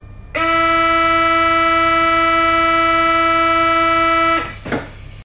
paris-metro-door-closing_24971.mp3